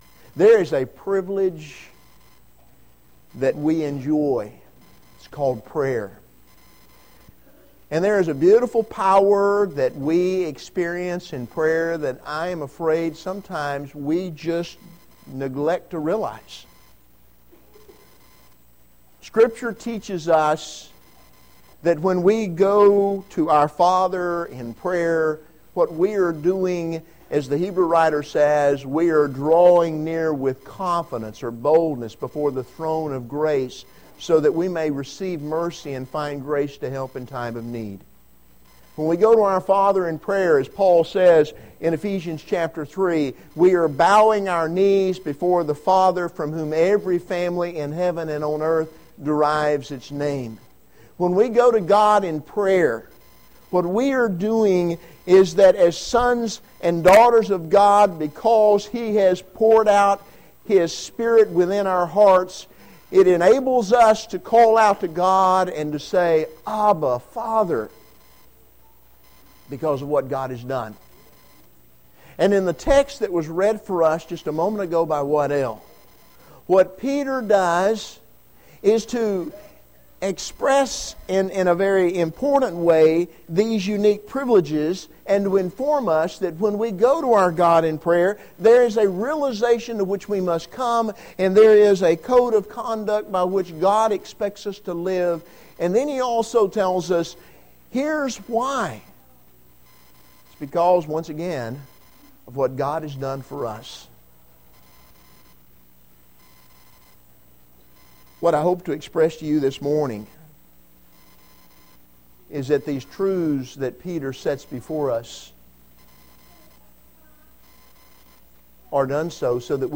2 Peter 3:1-13 Service Type: Sunday Morning